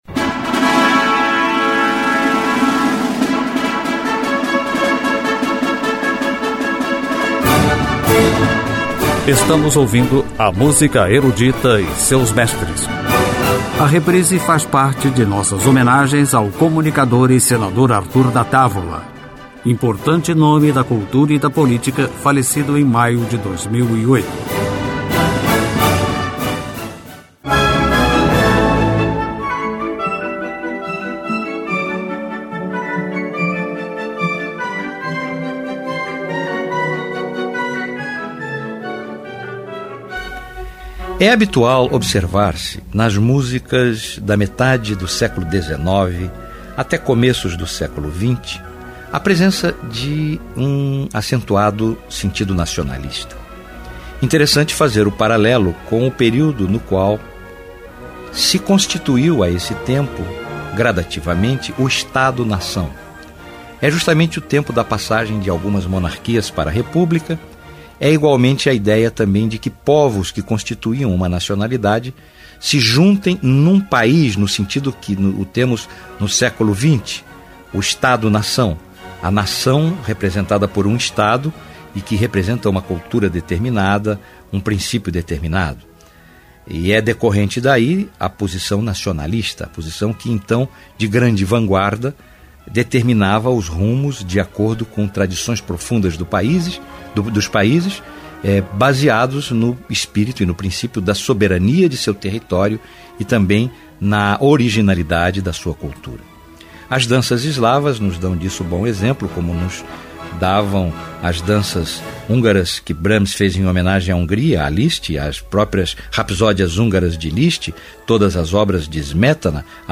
Música Erudita
Período romântico